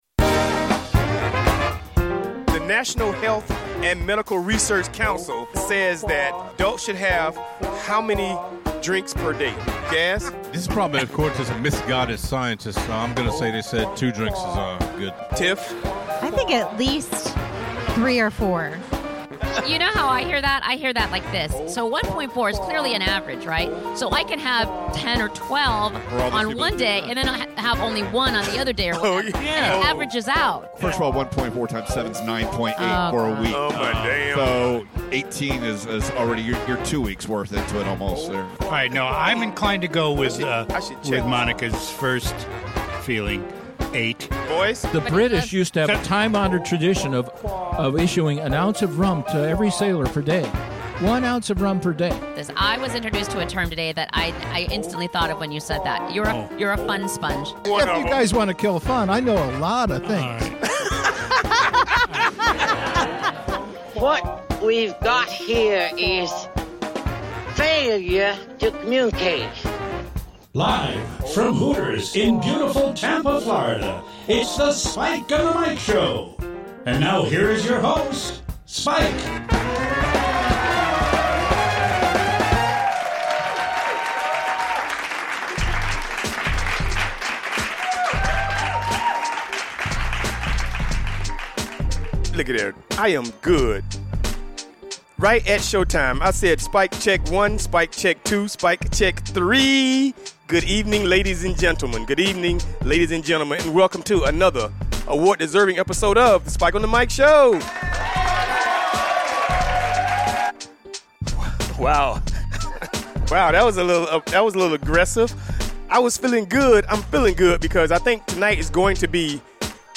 Airs Live from Hooters Tampa Mondays at 7pm ET